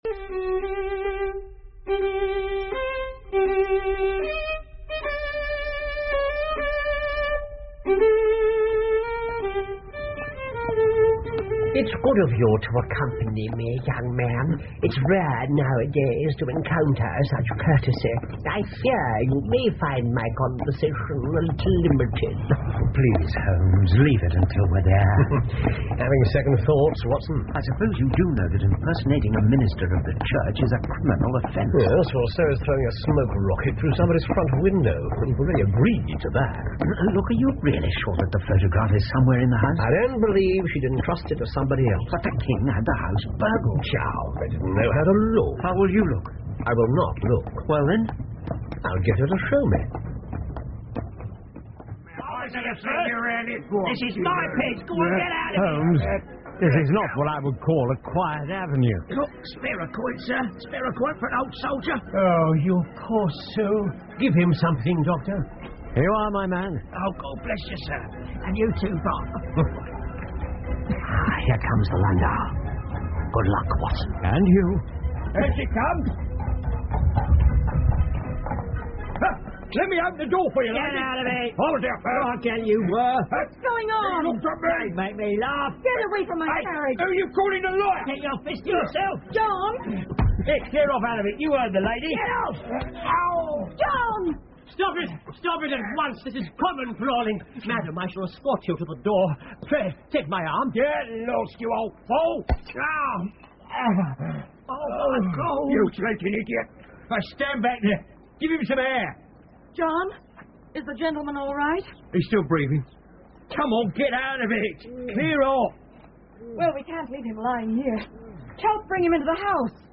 福尔摩斯广播剧 A Scandal In Bohemia 7 听力文件下载—在线英语听力室